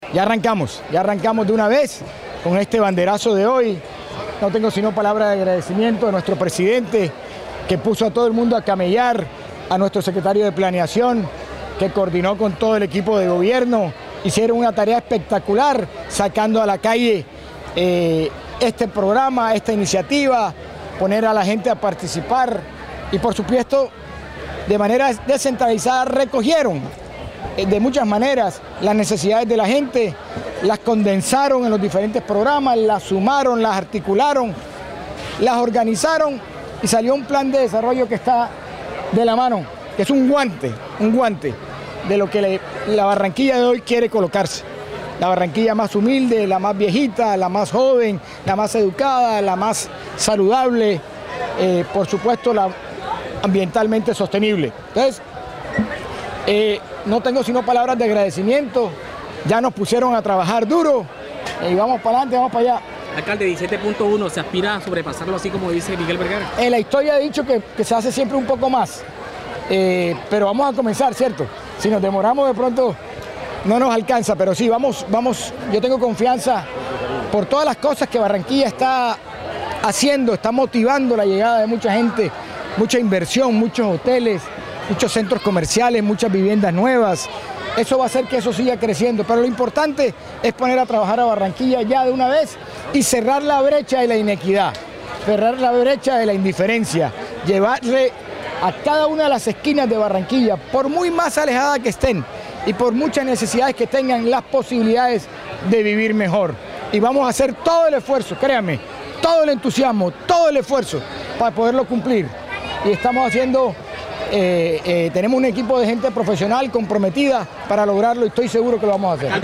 “Como barranquillero quiero expresar mi alegría por este gran logro, destacando el trabajo en equipo del Distrito y del Concejo, pero sobre todo quiero destacar la participación de la comunidad”, expresó el alcalde en el evento público en el que firmó el Acuerdo aprobado por la corporación en días pasados, en la plaza de la Intendencia Fluvial.